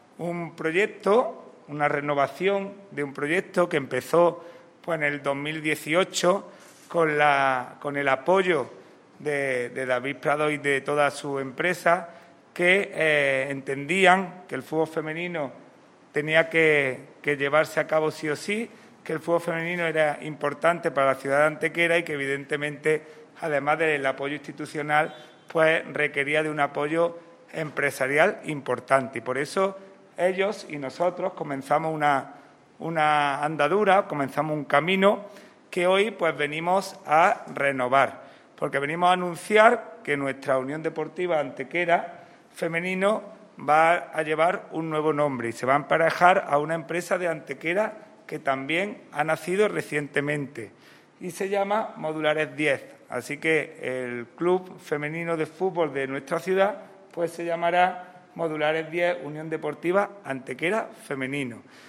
Por otra parte, en el transcurso de la rueda de prensa de presentación de esta nueva esponsorización se ha confirmado que el jueves 5 de agosto se disputará la segunda edición del torneo de fútbol femenino "Peña de los Enamorados", en el que participarán tanto el Modulares Diez U.D. Antequera Femenino como el Real Betis Féminas B de la Segunda División Femenina.
Cortes de voz